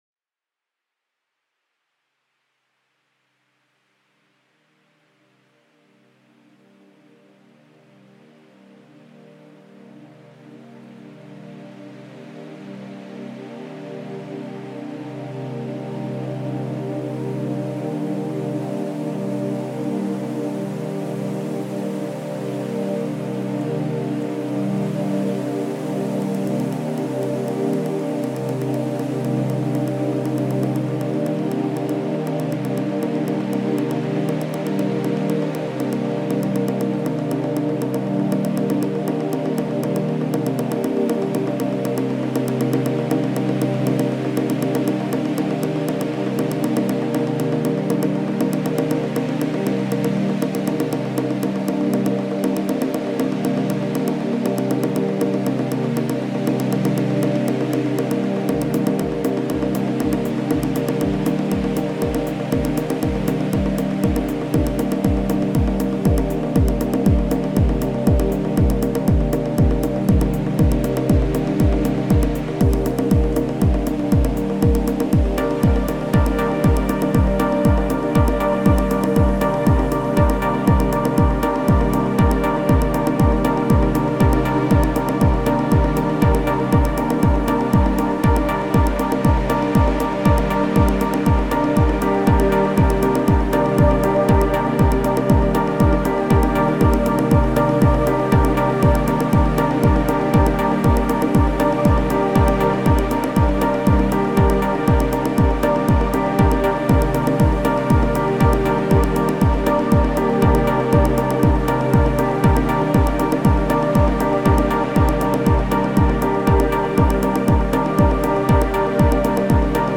Genre: Deep Techno/Dub Techno.